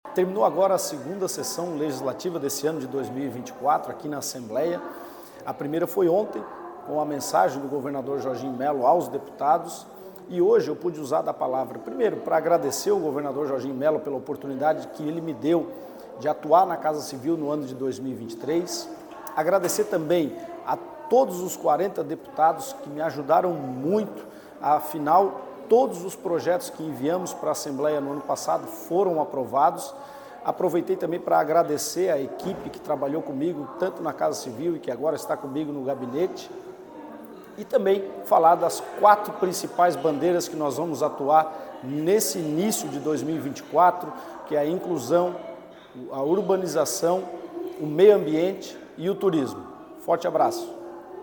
Foi dessa maneira que o deputado estadual Estêner Soratto (PL) subiu à tribuna da Assembleia Legislativa de Santa Catarina (Alesc) nesta quarta-feira (7).
AUDIO-FALA-DEP-SORATTO.mp3